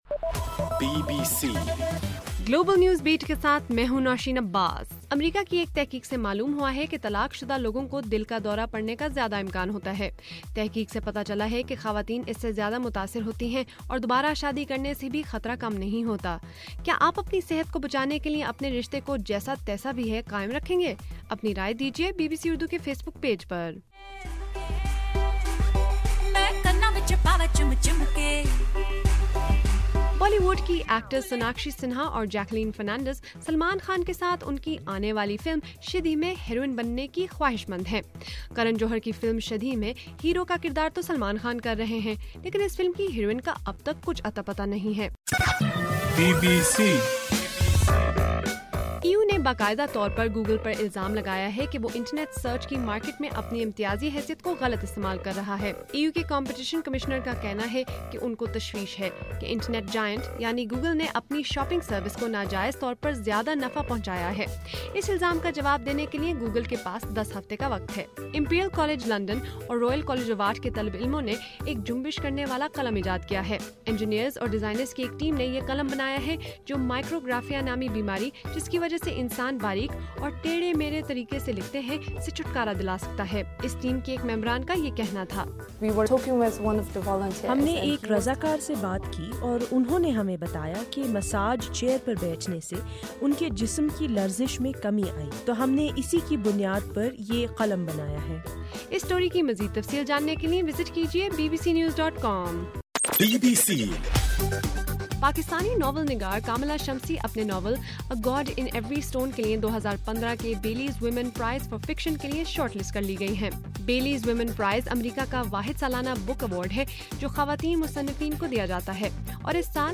اپریل 16:صبح 1 بجے کا گلوبل نیوز بیٹ بُلیٹن